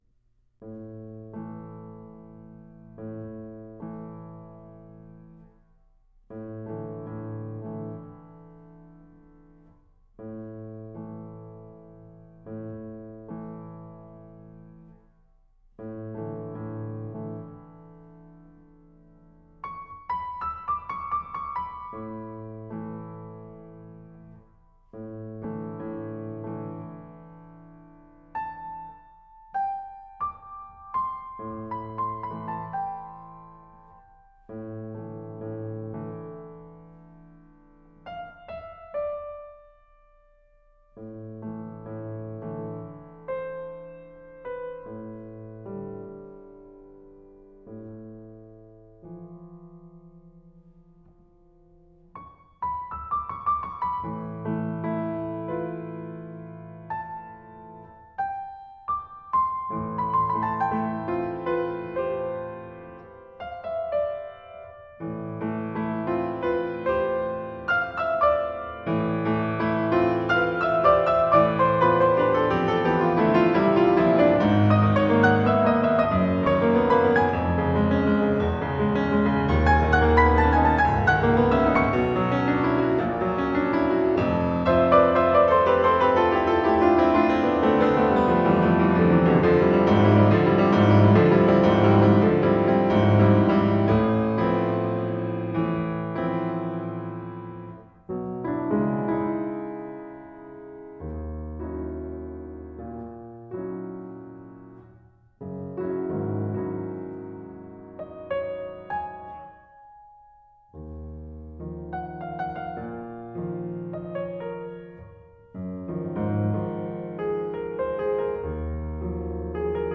for Piano (1980)
piano.